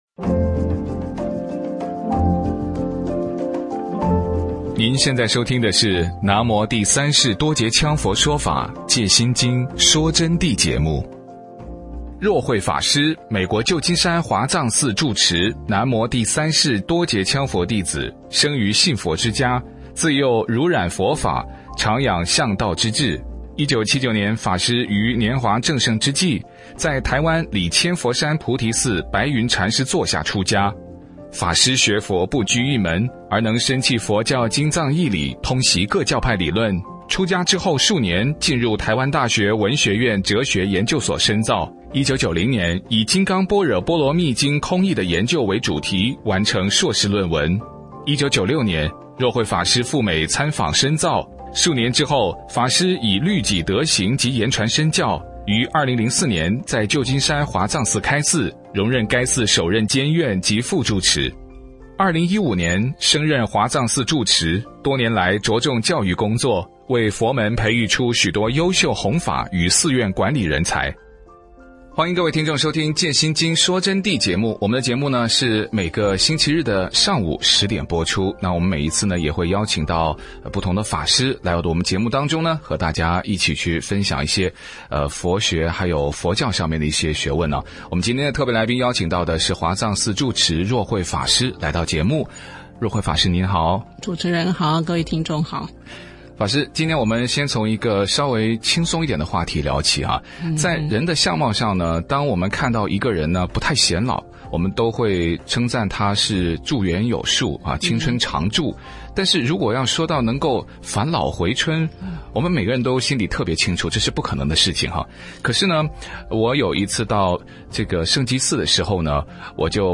佛弟子访谈（二十）南无羌佛返老回春的真相是什么？佛教界的法王是什么概念？